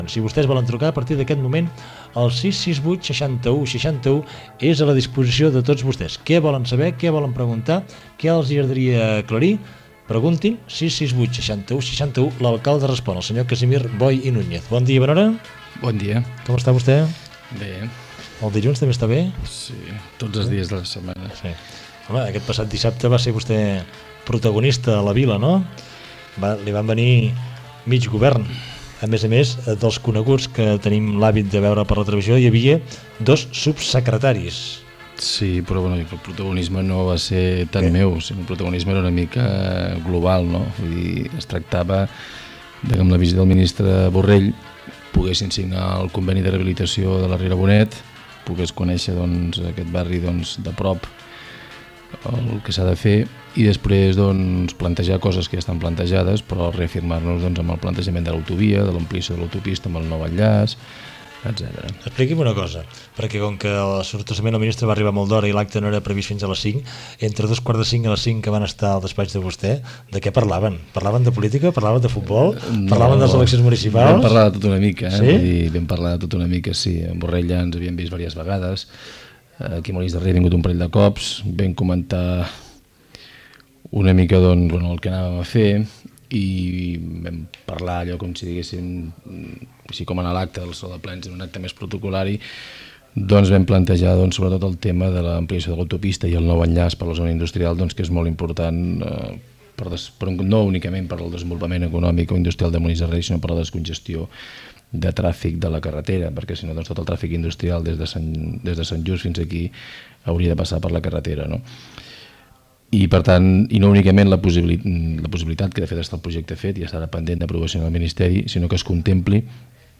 trucades telefòniques amb preguntes i demandes a l'acalde de Molins de Rei Casimir Boy Gènere radiofònic Participació